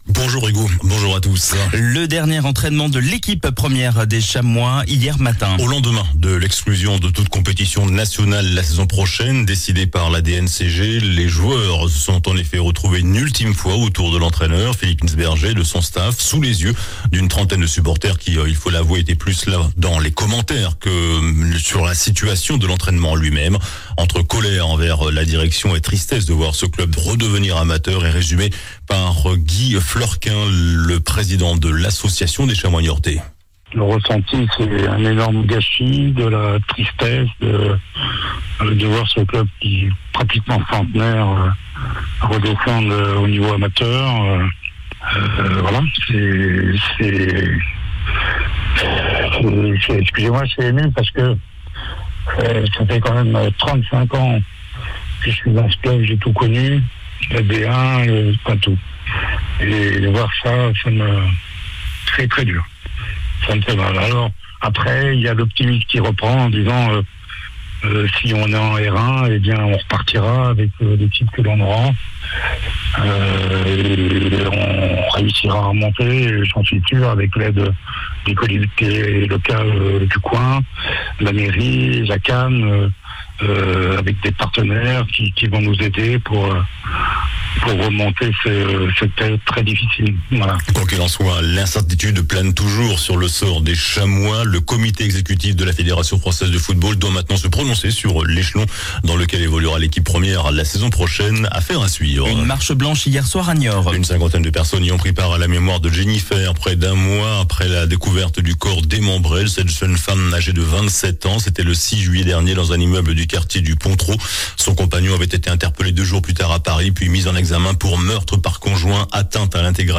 JOURNAL DU SAMEDI 03 AOÛT